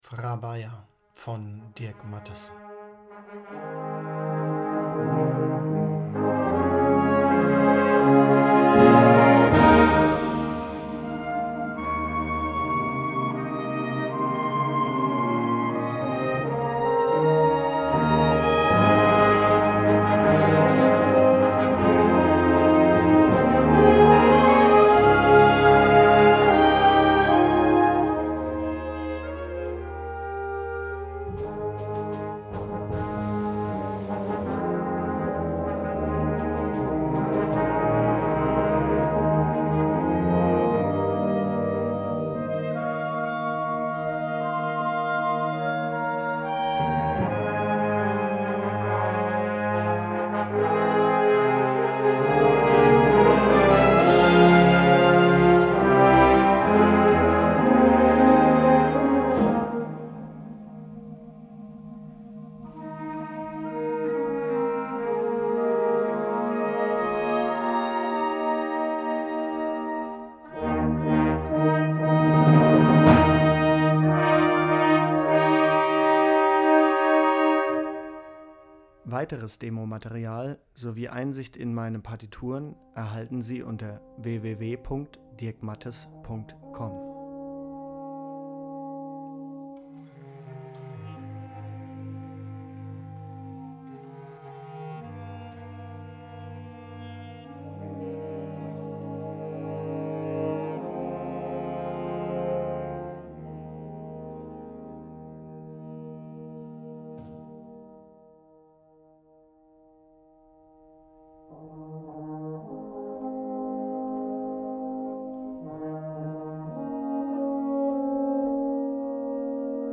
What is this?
Works for Concertband: